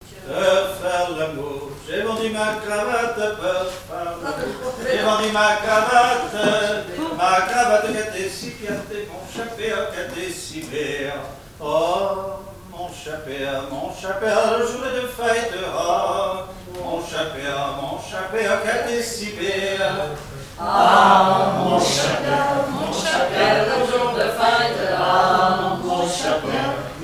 Genre énumérative
7e festival du chant traditionnel : Collectif-veillée
Pièce musicale inédite